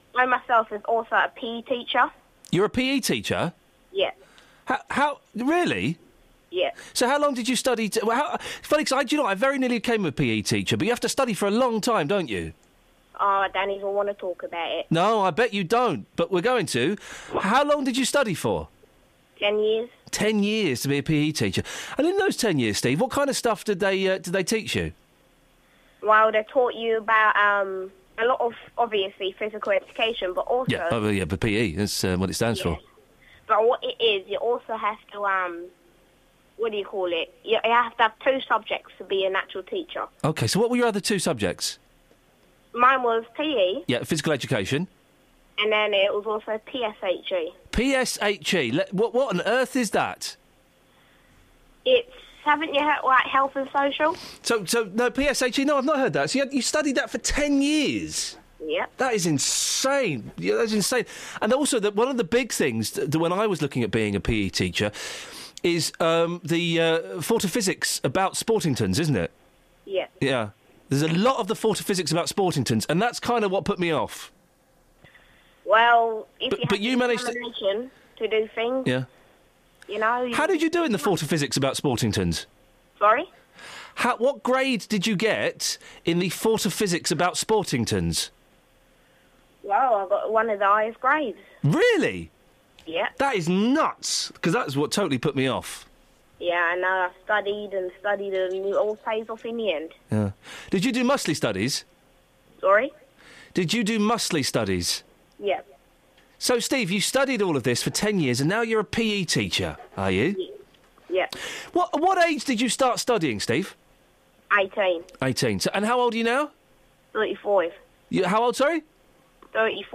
PRANK! Or is it? Well, yeh is, but is it? Yes.